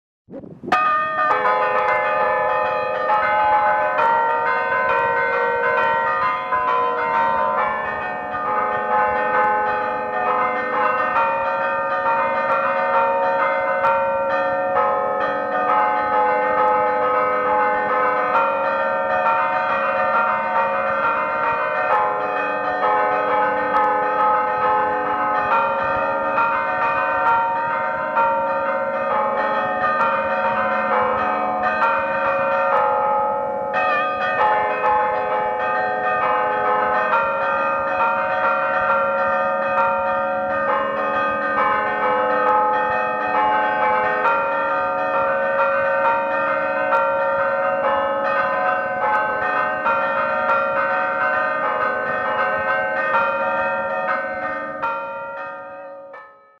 campane di romanoro